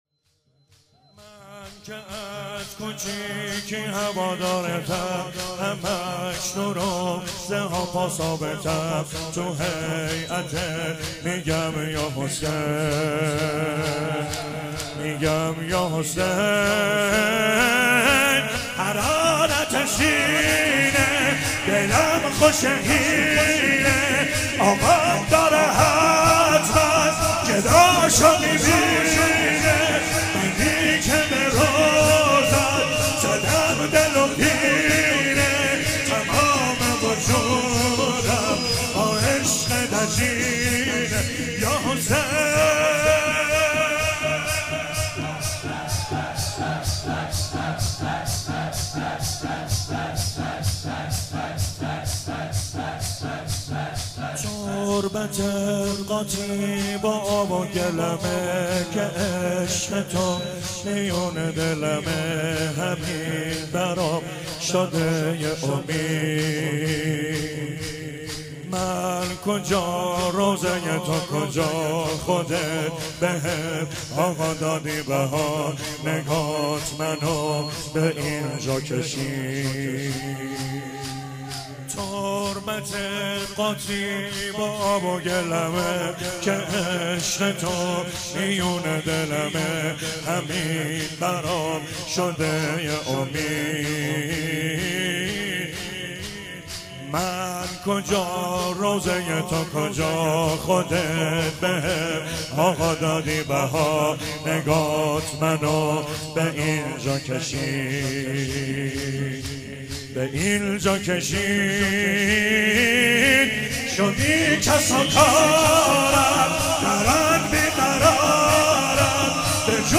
محرم شب اول تا شام غریبان